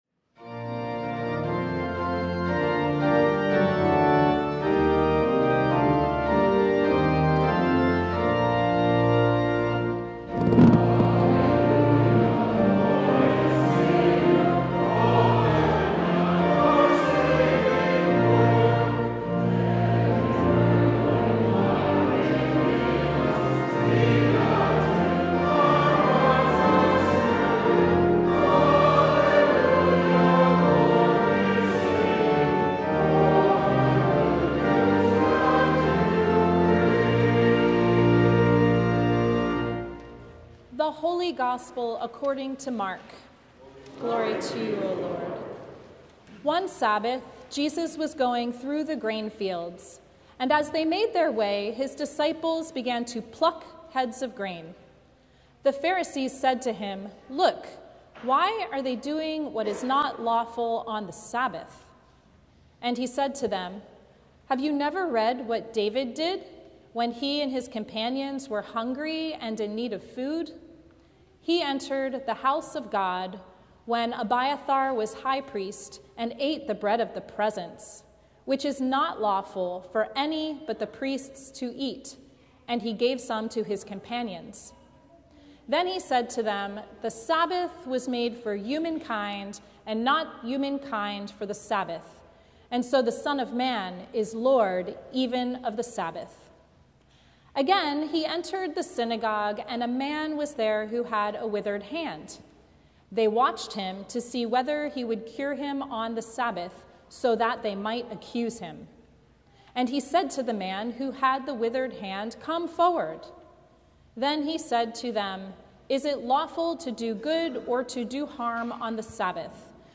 Sermon from the Second Sunday After Pentecost